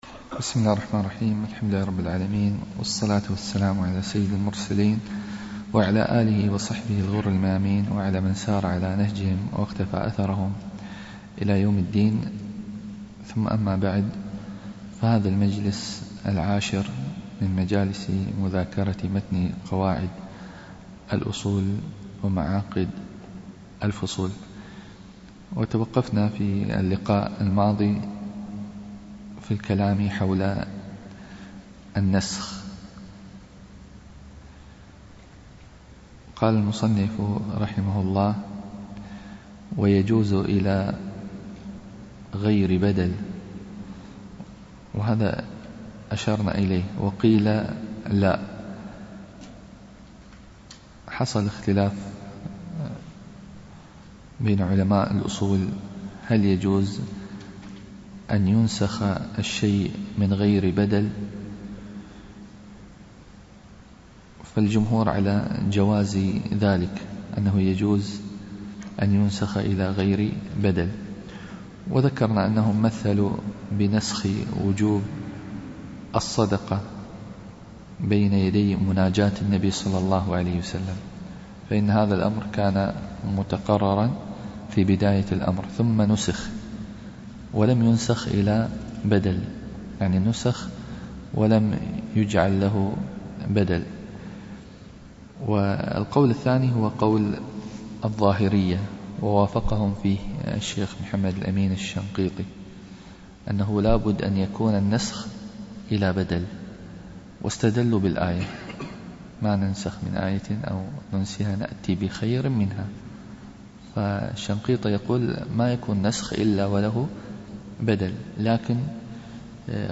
الدرس العاشر